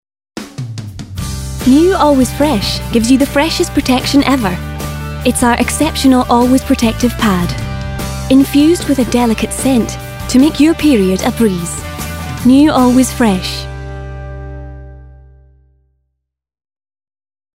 SCOTS. Smooth and calming to upbeat irreverence. Personable lass. A natural VO. Avid Muso.
Her accents range from Scottish East Coast, Edinburgh and Highlands to RP and Irish, and voice age ranging from teens to late 20s.